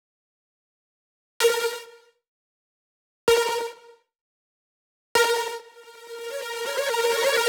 Index of /VEE/VEE2 Melody Kits 128BPM